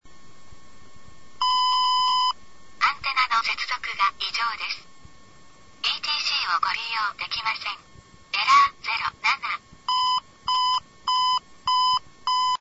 DIU-9000 音声案内　（MP3録音ファイル）